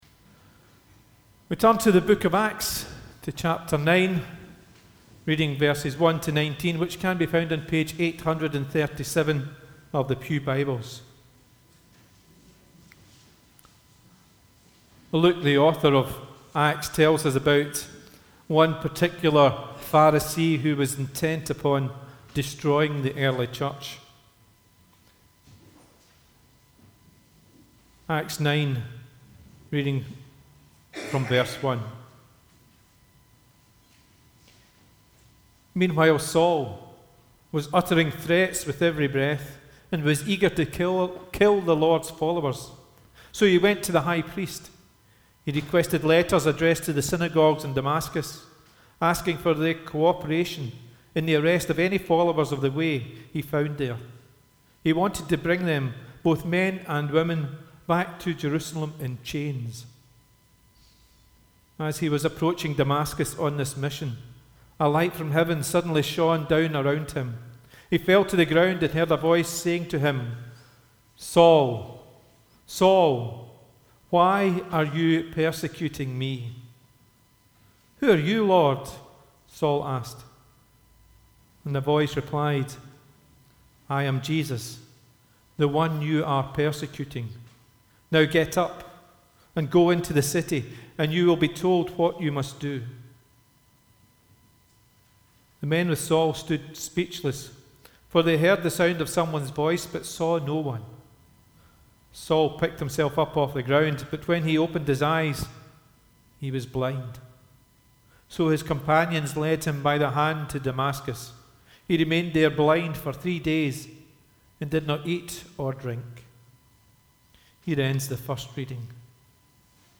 The reading prior to the sermon is Acts 9: 1-25